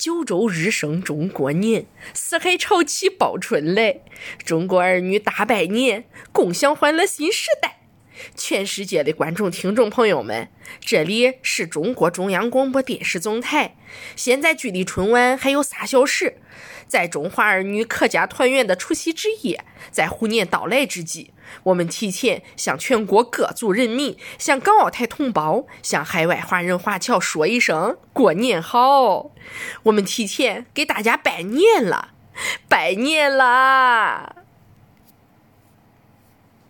年轻活力-电台主持